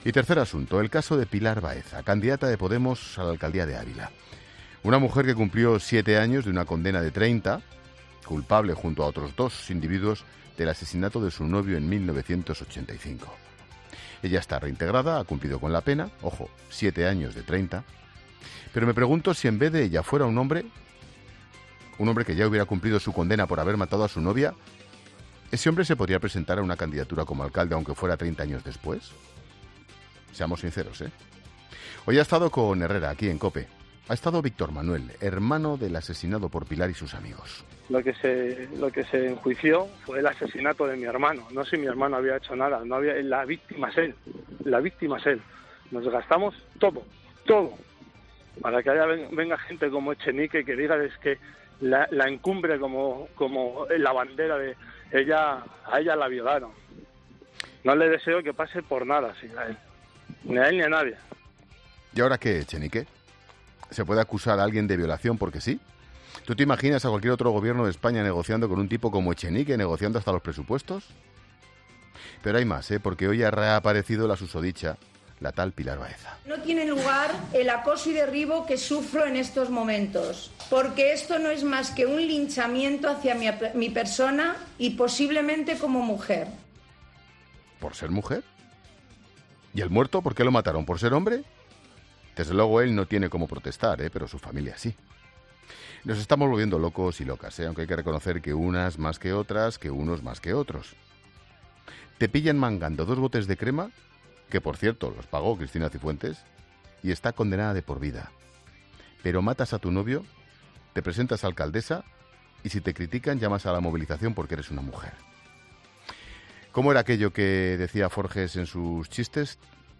En su monólogo de las 19 horas de este martes 5 de marzo, el director de La Linterna, Ángel Expósito, se ha preguntado si, “en el caso de que esta fuera la misma situación pero con un hombre”, podría presentarse a la misma alcaldía 30 años después de “matar a su pareja”.